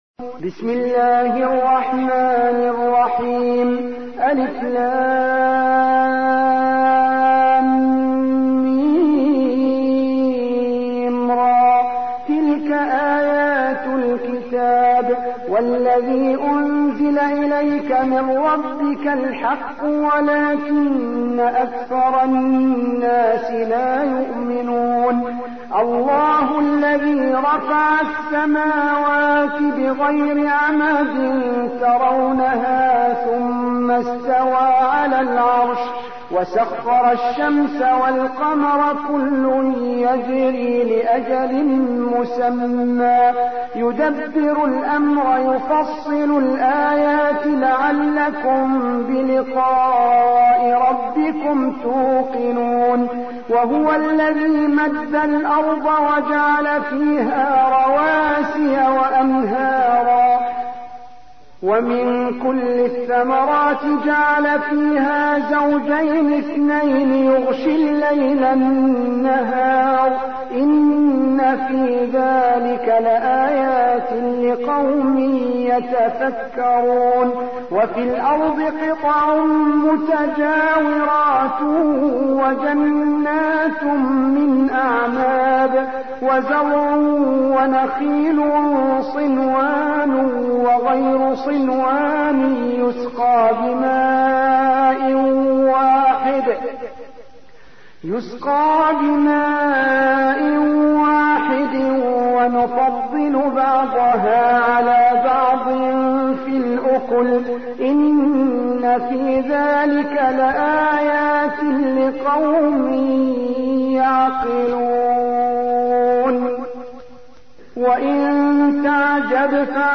13. سورة الرعد / القارئ